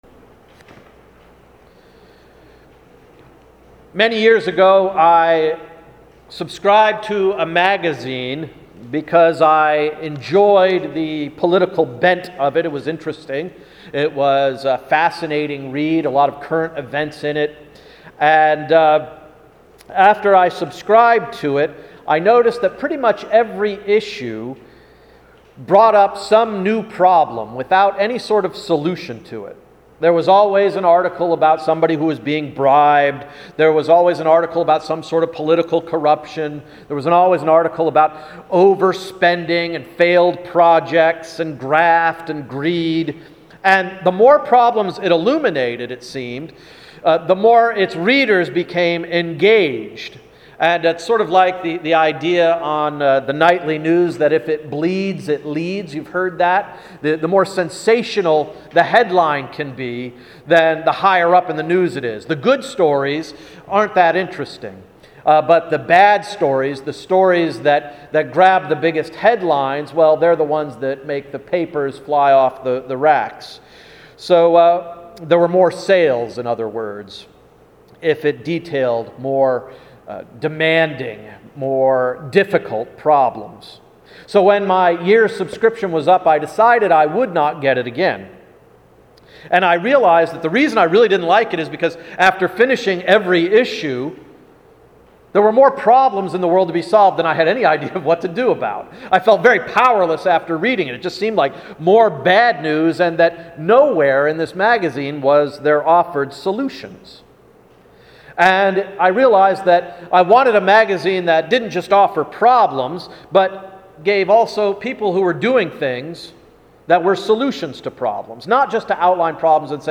“The God Season”–Sermon of August 18, 2013